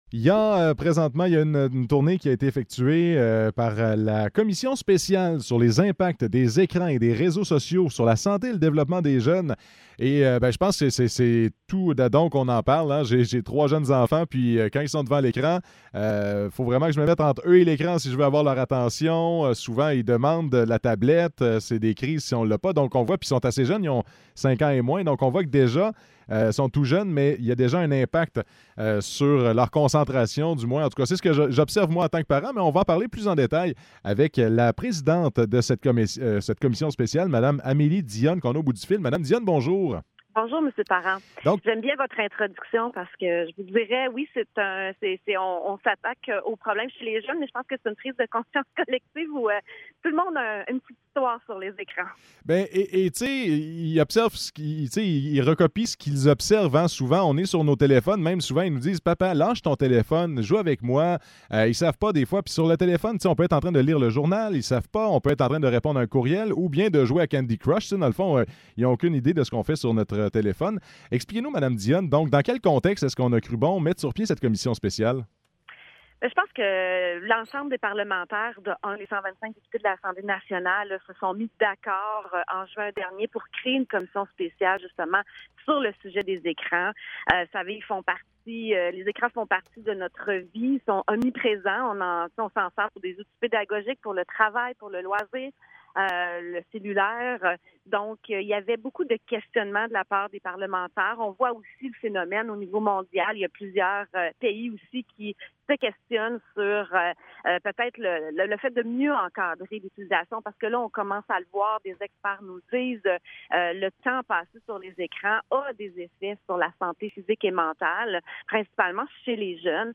Entrevue avec Amélie Dionne